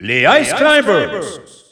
Announcer pronouncing Ice Climbers in French in victory screen.
Ice_Climbers_French_Alt_Announcer_SSBU.wav